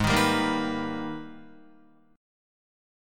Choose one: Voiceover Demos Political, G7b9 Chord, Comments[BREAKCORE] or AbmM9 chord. AbmM9 chord